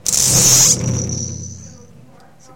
乐器：Bassdrum声学，汤姆，击打，邦戈和刷子。
Tag: CHASE 狂野 ACTION 电影 生存 电影 ACTION-鼓 CHASE圆桶